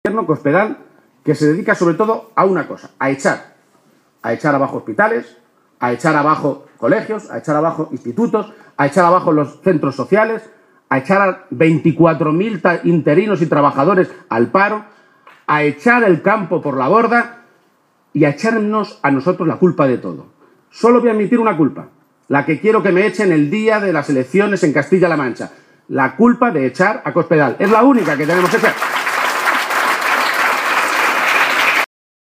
El secretario general del PSOE de Castilla-La Mancha, Emiliano García-Page, ha protagonizado esta tarde un acto electoral en la localidad toledana de Illescas en el que ha dicho que la campaña empieza a ir muy bien para el PSOE y ha animado a oos militantes, simpatizantes y votantes socialistas a seguir “porque a nosotros no nos van a quitar la moral Gobiernos como los de Rajoy y Cospedal, que no tienen moral”.